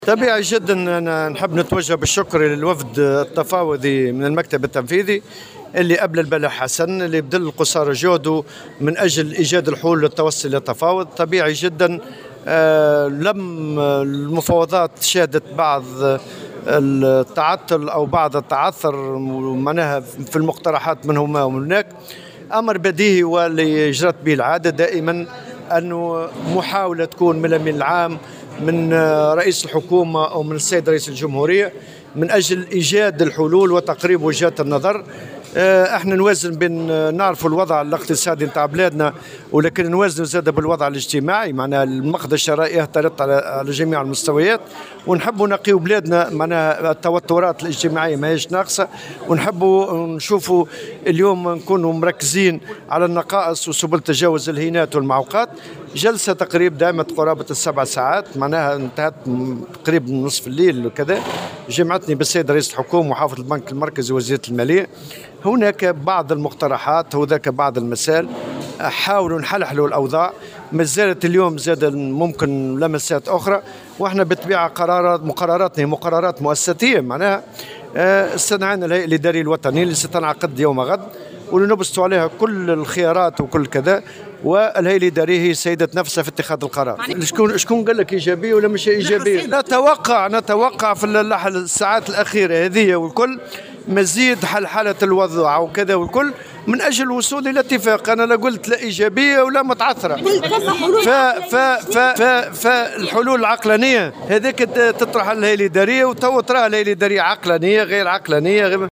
وأكد الطبوبي، في تصريح لمراسل الجوهرة أف أم، خلال زيارته اليوم السبت إلى المنستير، ضرورة تقدم أحد طرفي المفاوضات بمبادرات قصد تقريب وجهات النظر، لحماية البلاد من أي توترات اجتماعية، في ظل تواصل اهتراء المقدرة الشرائية للأجراء، مع الأخذ بعين الاعتبار للوضع الاقتصادي الذي تمر به البلاد.